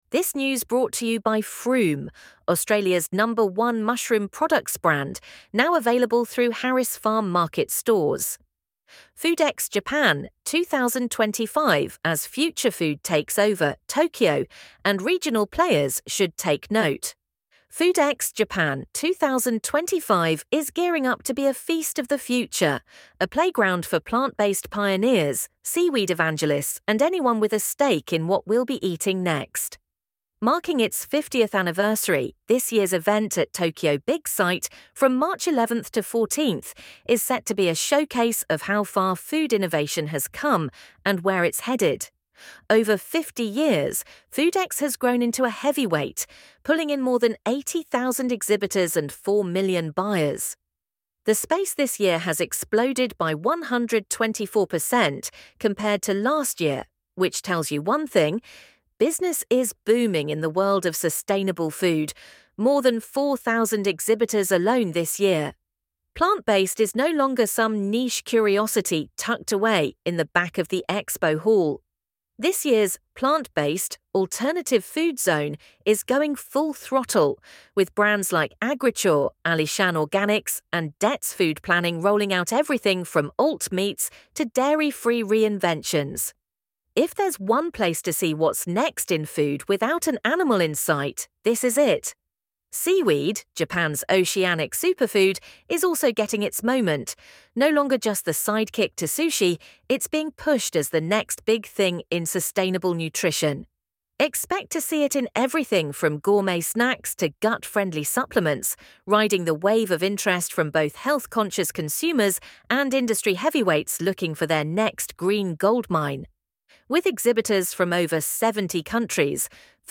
LISTEN TO THIS AUDIO ARTICLE FOODEX Japan 2025 is gearing up to be a feast of the future, a playground for plant-based pioneers, seaweed evangelists, and anyone with a stake in what we’ll be eating next.